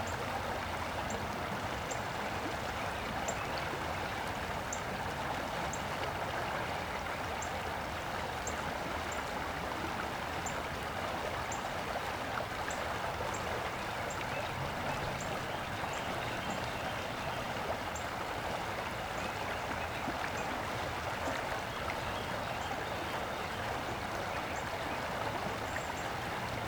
Afternoon Suburban Park Babbling Brook Birds Tetramic 01_ambiX.ogg